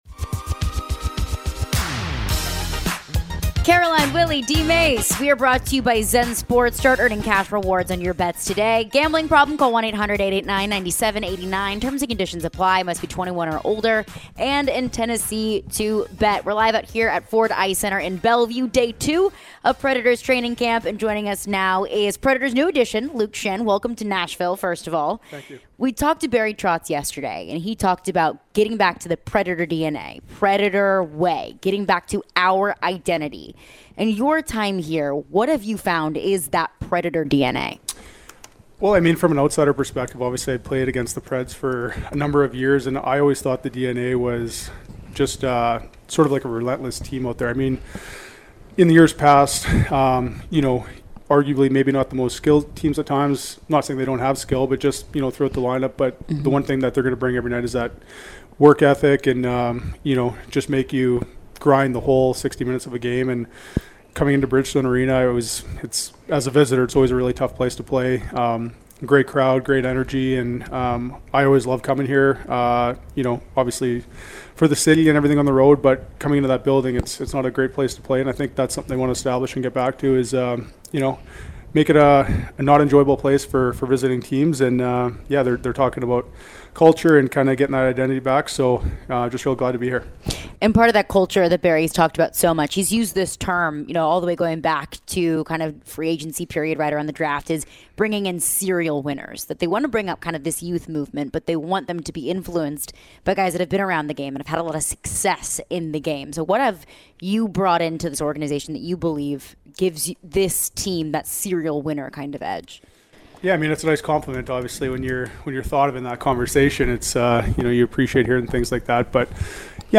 In his sit-down interview, Luke describes what it's like being a Nashville Predator and what Barry Trotz is trying to build. Later in the conversation, he elaborates on the new syle of play the Predators are working on and what it's like playing with a few familiar faces.